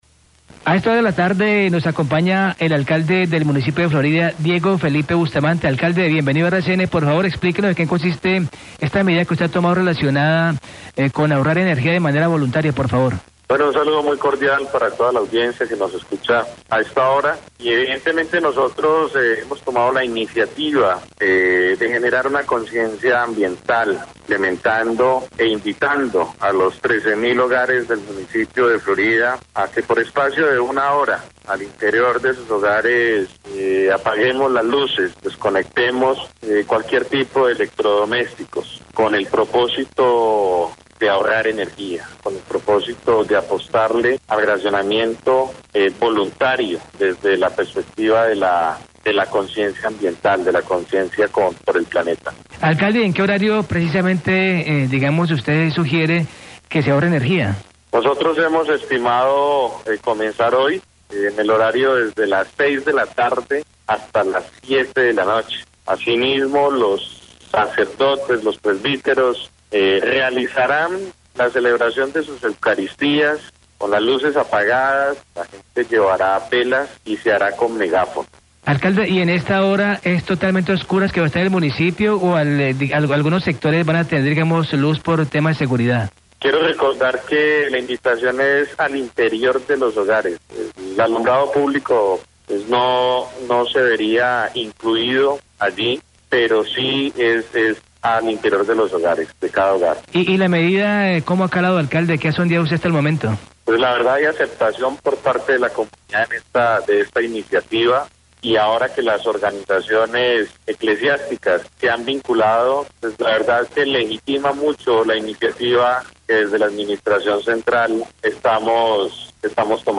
Radio
Entrevista con el alcalde del municipio de Florida (Valle), quien hace la invitación para iniciar el ahorro de energía en su localidad con cortes voluntarios de energía por horas.